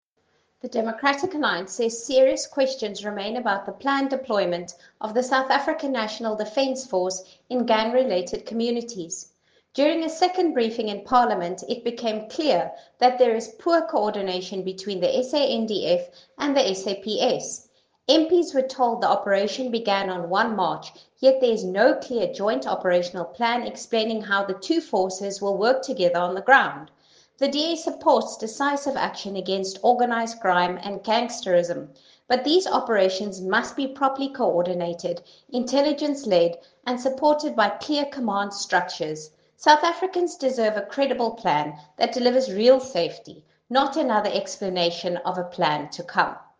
Afrikaans by Lisa Schickerling MP.